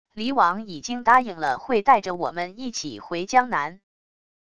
黎王已经答应了会带着我们一起回江南wav音频生成系统WAV Audio Player